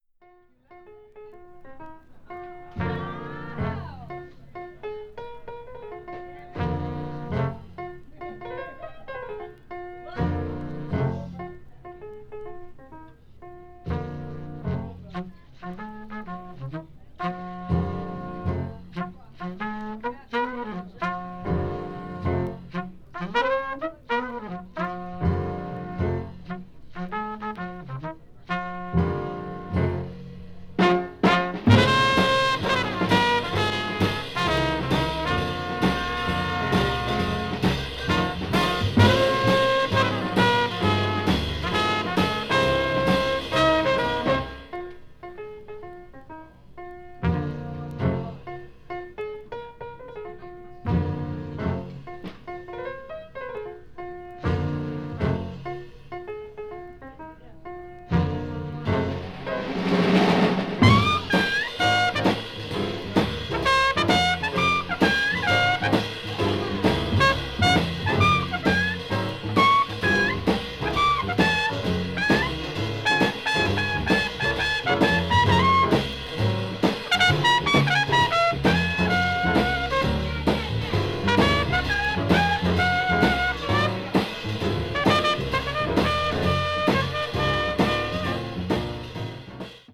media : EX+/EX+(some slightly noises.)
hard bop   modern jazz